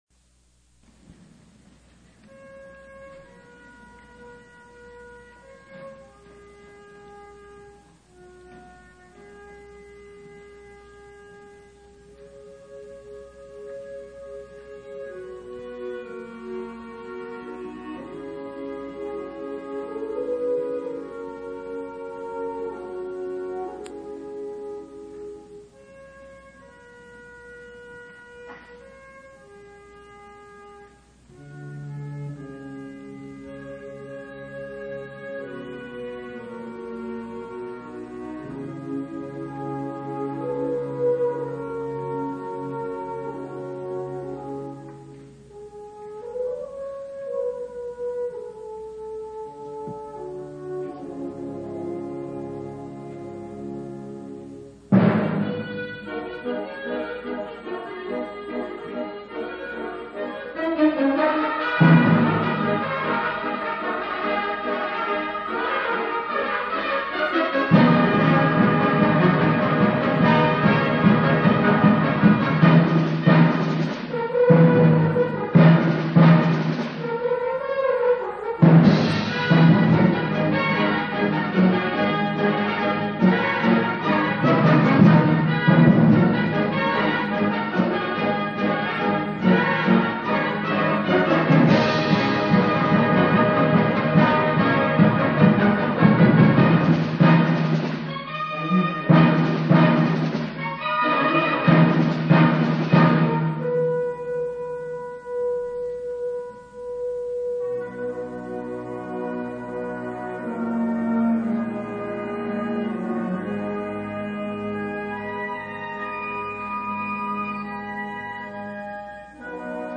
知多高吹奏楽部コンクールの記録
愛知県大会１部 金賞